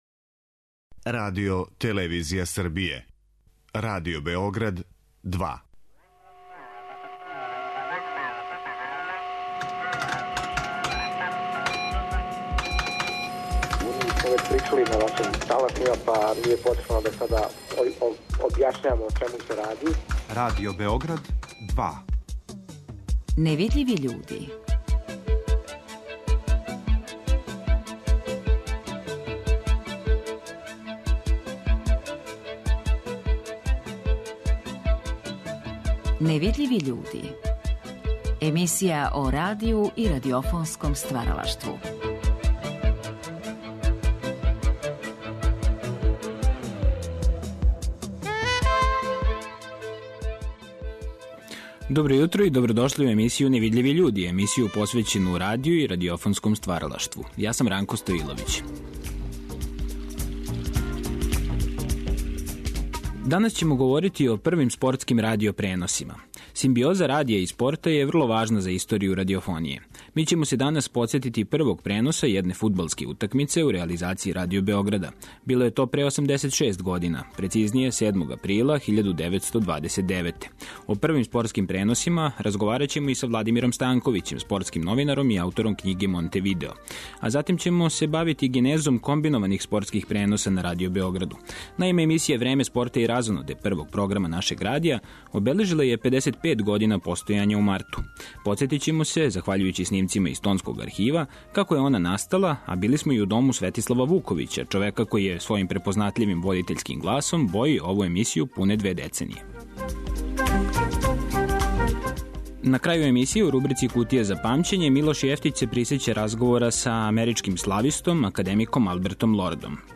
Чућете њихова сећања из ризнице нашег Тонског архива.